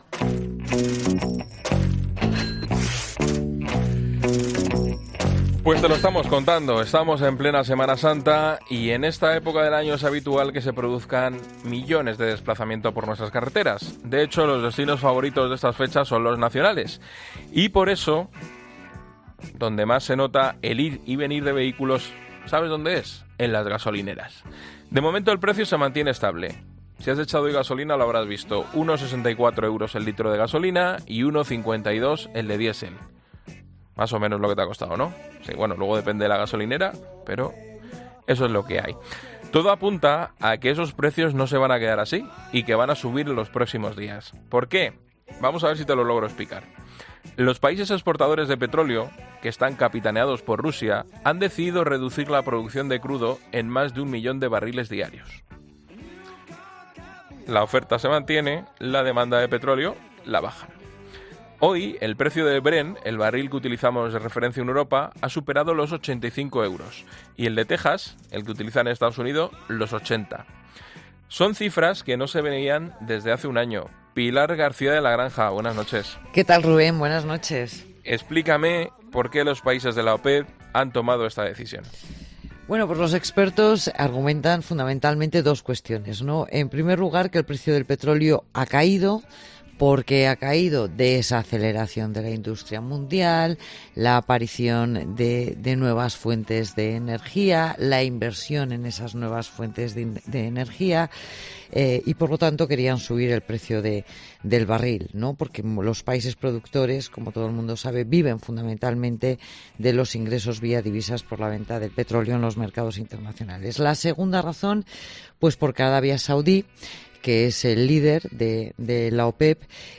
La periodista económica analiza en La Linterna la decisión de la OPEP sobre los barriles de crudo y cómo nos afectará en los próximos días